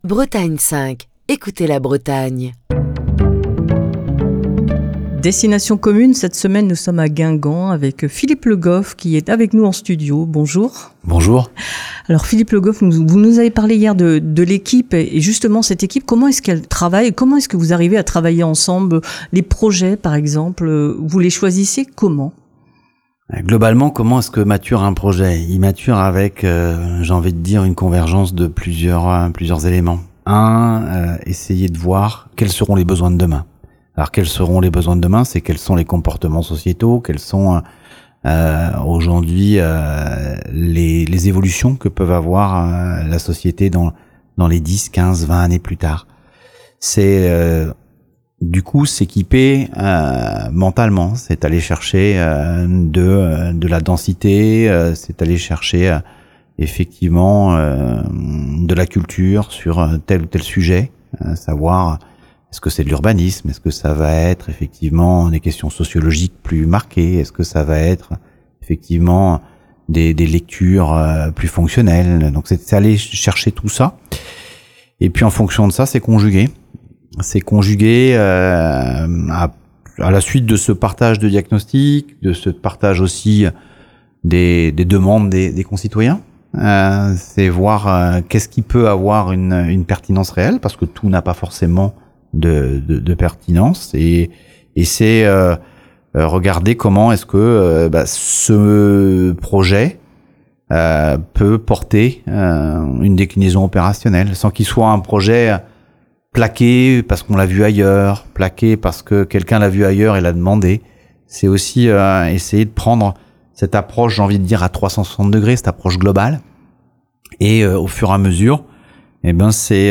Philippe Le Goff, le maire de Guingamp, qui vous invite à découvrir sa ville et les divers aspects de son quotidien d'élu.